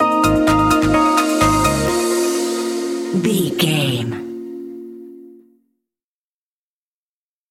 Aeolian/Minor
B♭
groovy
uplifting
energetic
drums
drum machine
synthesiser
bass guitar
funky house
upbeat
instrumentals